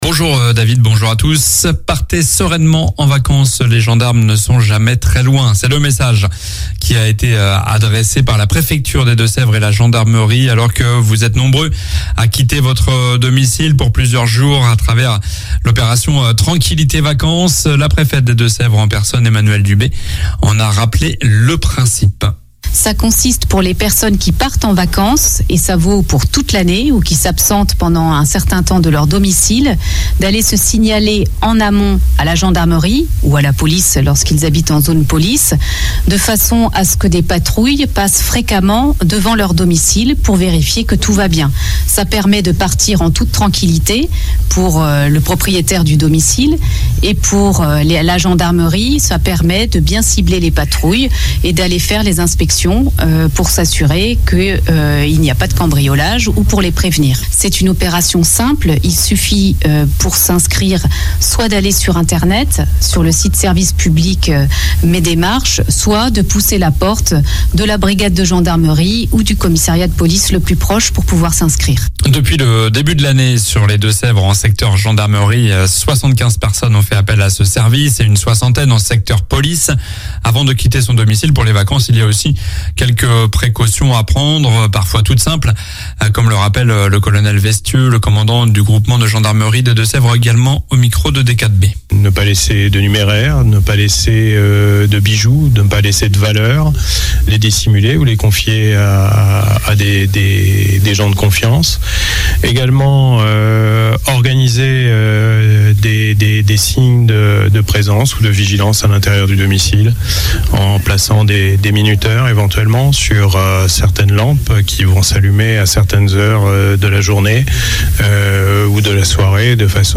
Journal du mardi 26 juillet (matin)